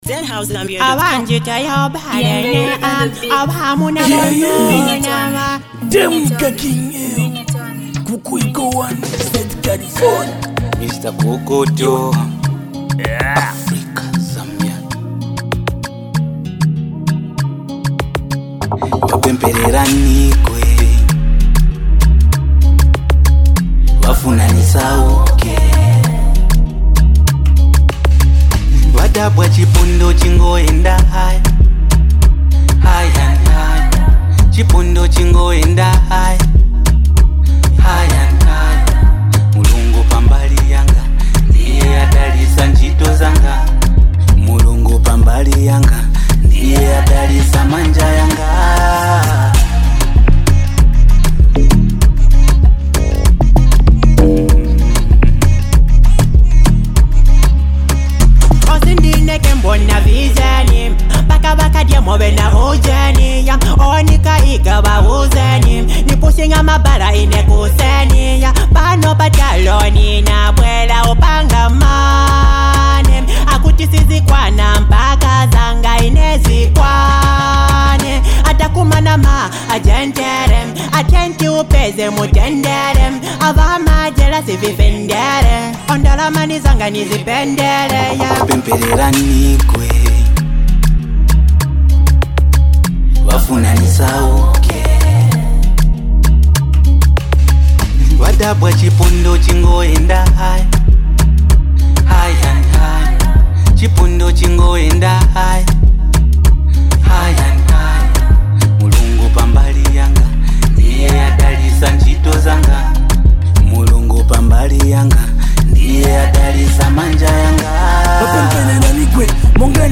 Rising with unstoppable energy